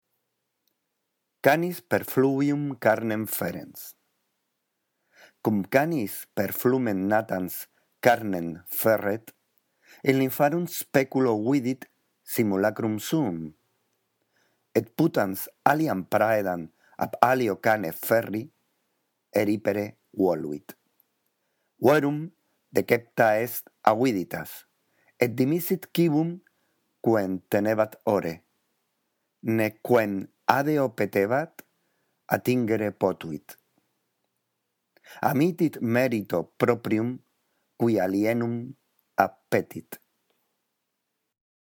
La audición de este archivo te ayudará en la práctica de la lectura del latín: